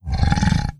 Monster_Hit6.wav